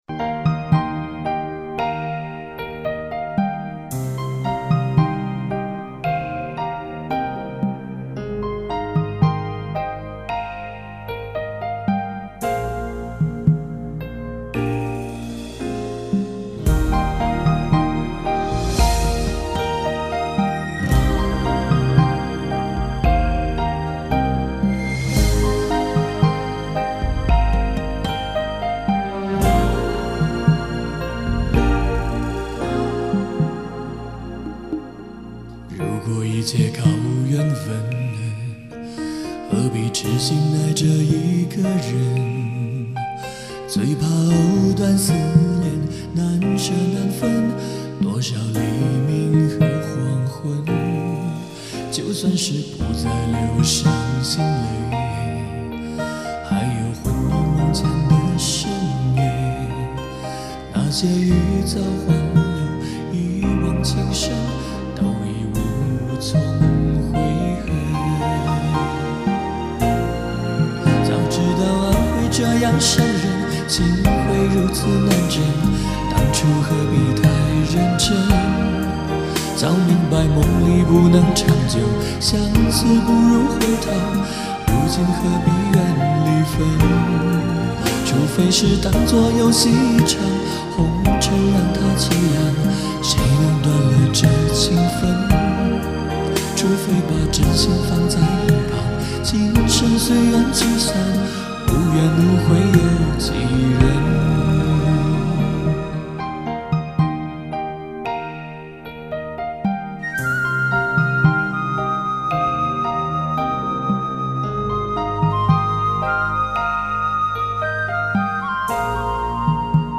低音版